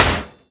metal_impact_light_thud_01.wav